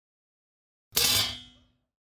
Металлическую крышку кастрюли плотно закрыли